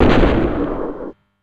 Super Smash Bros. game sound effects
Fox - Fire Jump.wav